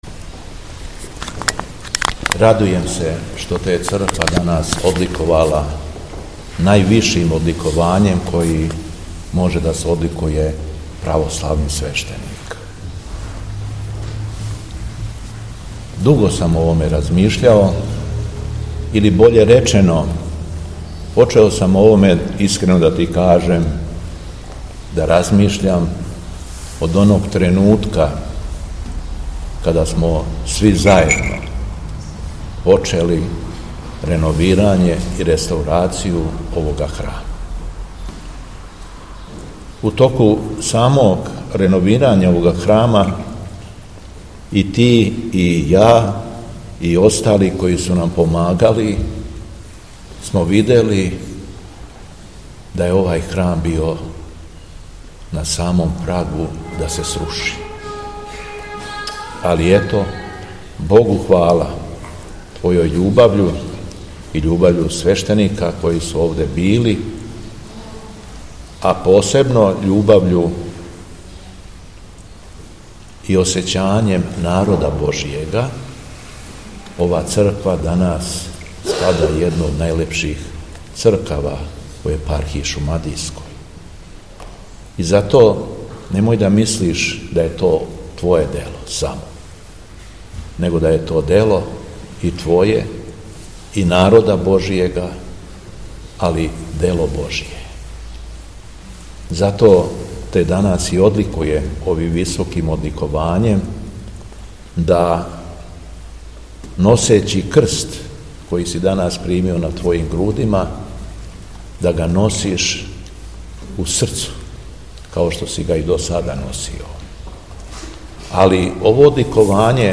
Духовна поука Његовог Високопреосвештенства Митрополита шумадијског г. Јована